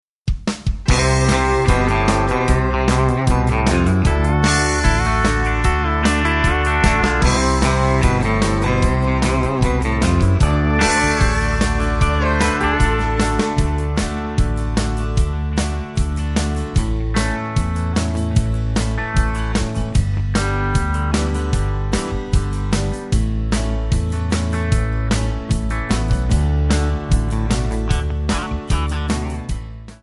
Backing track files: Country (2471)
Buy With Backing Vocals.
Buy With Lead vocal (to learn the song).